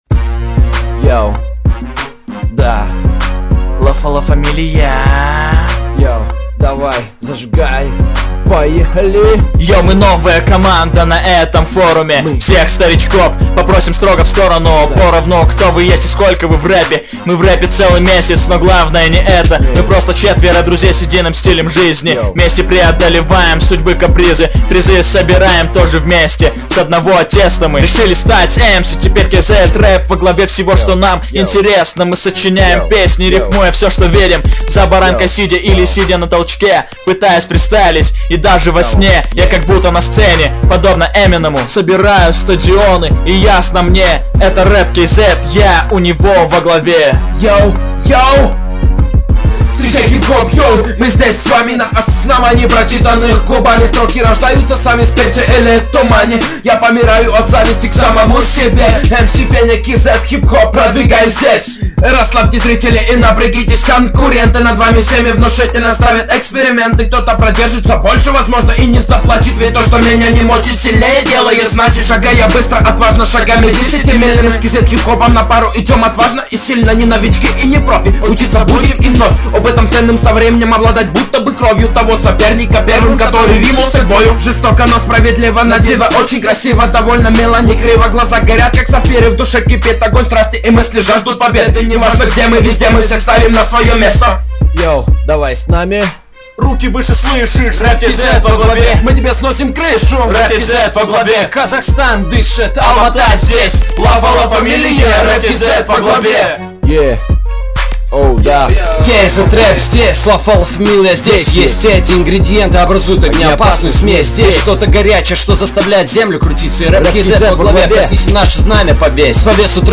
• Исполняет: Lafalafamilia
• Жанр: Фолк
Это наш первый общий трэк ))) Команде где-то месяц тогда был )) знаю, что хреново...